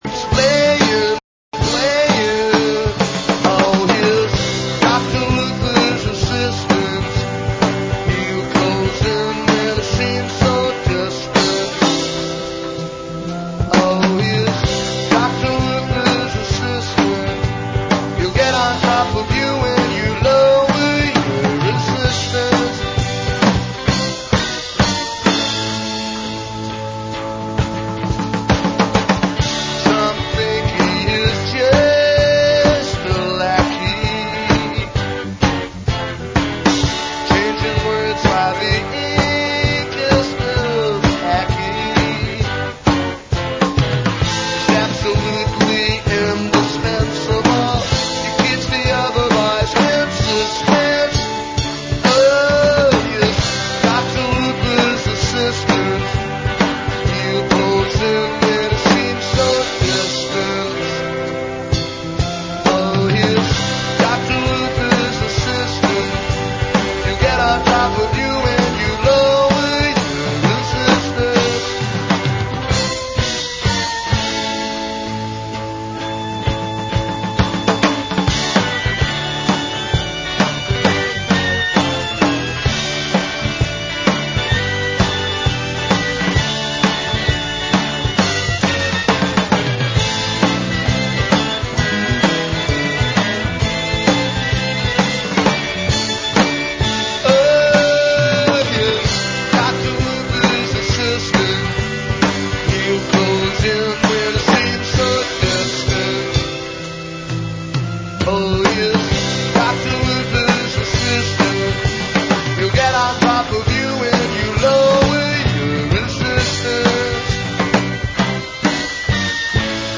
live from John Doe Books and Records in Hudson, NY.